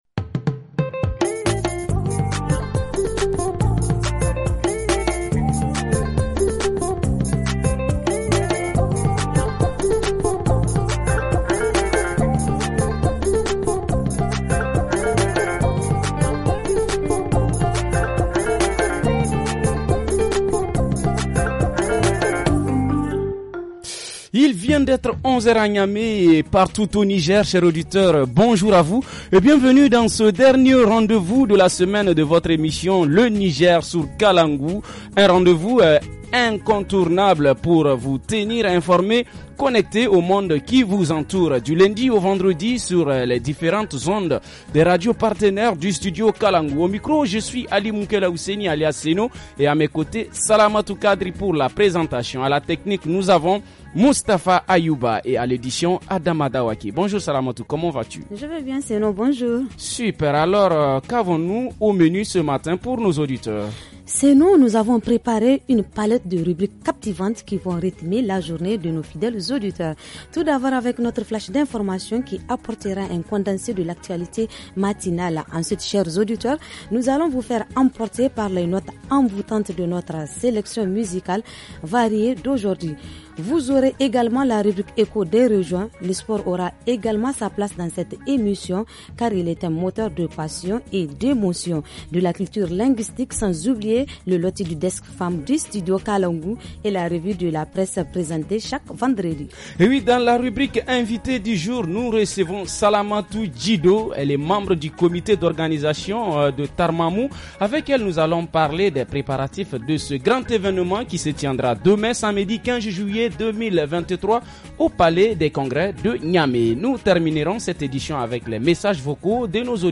–Reportage Région : Les dépenses liées aux mariages à Maradi ;